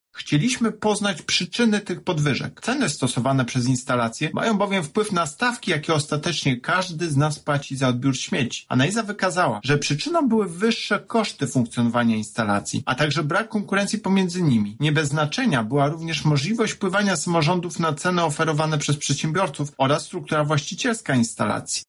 W kolejnym etapie naszych prac skupiliśmy się właśnie na RIPOK-ach – mówi Tomasz Chróstny, prezes UOKiK.